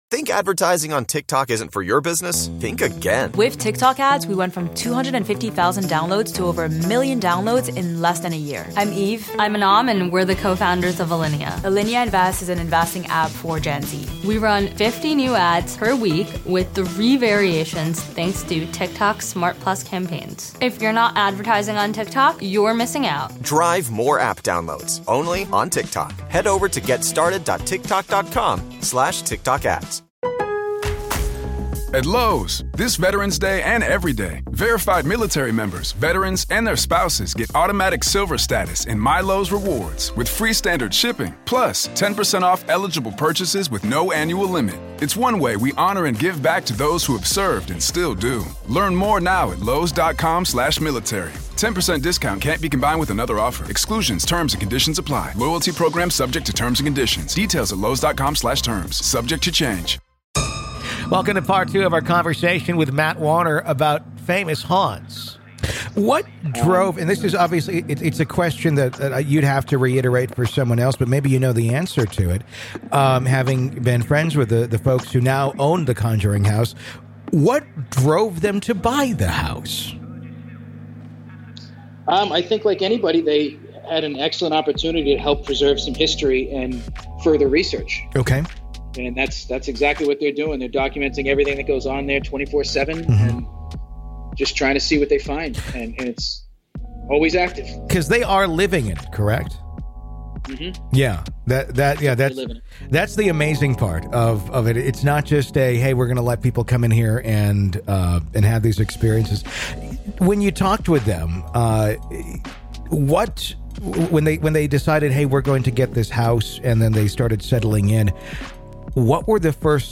Today we discuss his adventures investigating the dead all around the country. This is Part Two of our conversation.